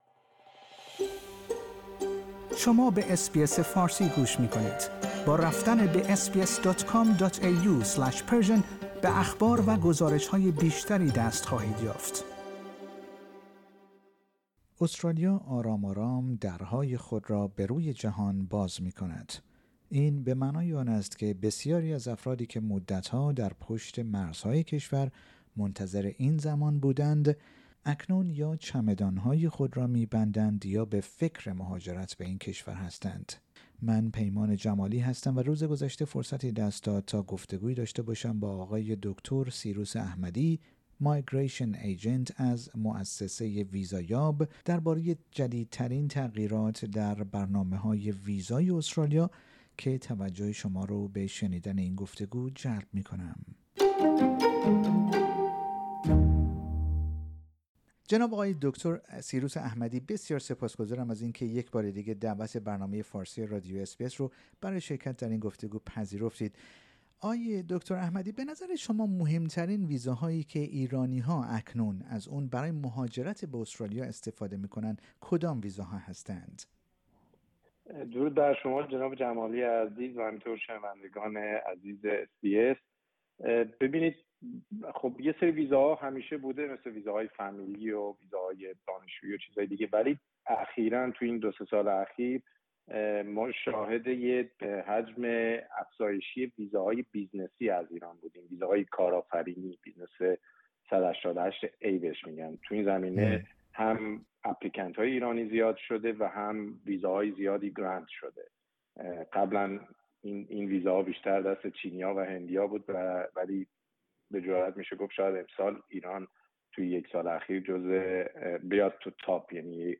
در گفتگو با رادیو اس بی اس فارسی